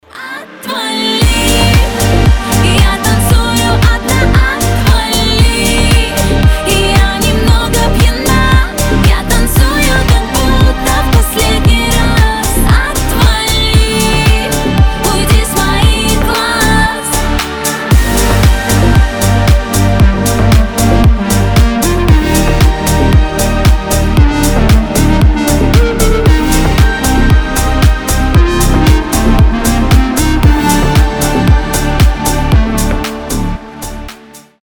поп , танцевальные